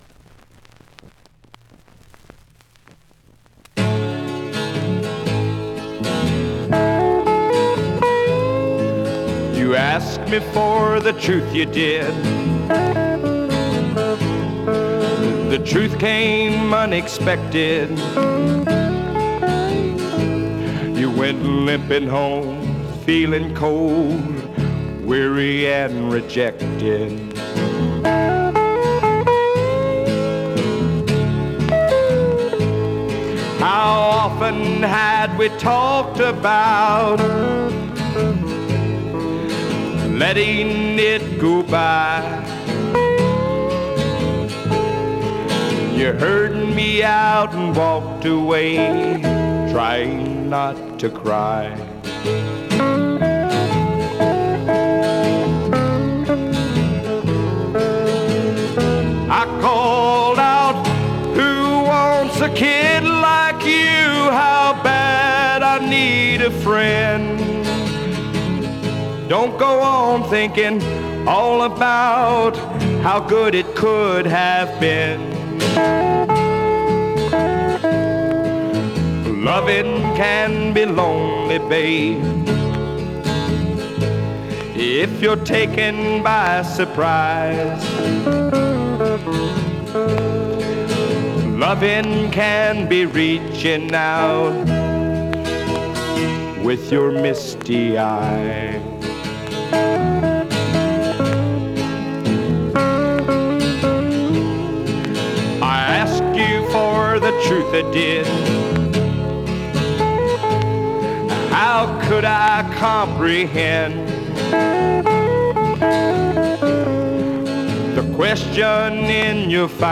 your misty eyes is lyrically the most complete song i think ive ever written.  i knew what i wanted to say when i wrote it and how to say it just happened. musically, it is by far the most complex. there are five time signature changes in it that make for difficult execution of a smooth sound. after five takes i settled for the third which is far from correct. i think i was ready to tackle it towards the end of the session but we had already clocked up a fortune by that time and i just couldnt ask for more.